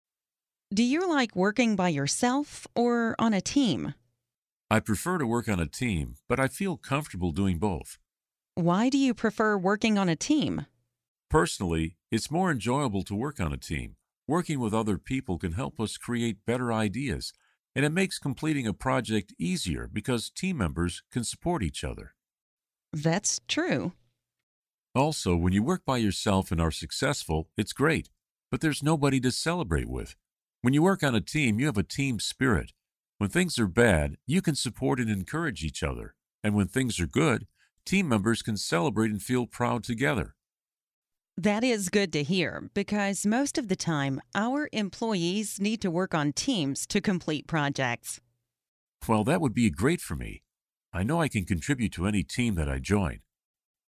Speak professional and fluent English. Learn different ways to answer the interview question 'Do you like working by yourself or on a team?', listen to an example conversation, and study example sentences